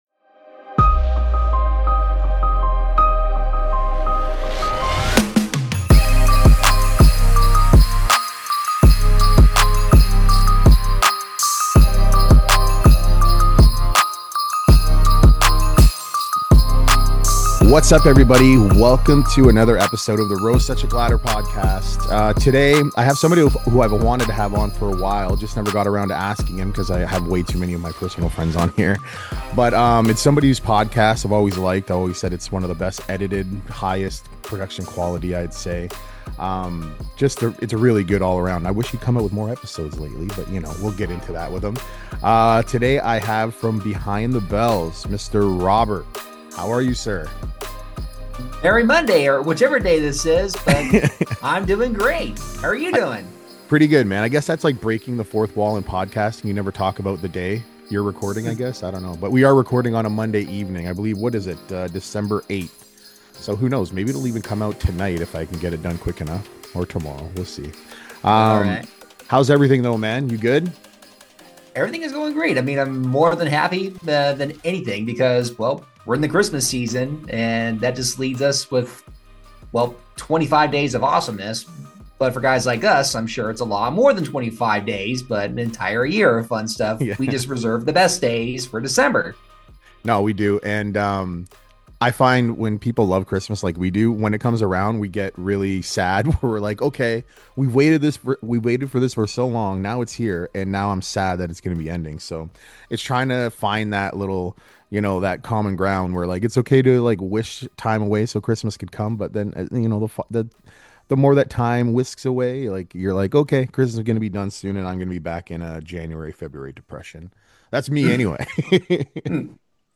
This was one of my easiest interviews and one of my favorites I’ve ever done.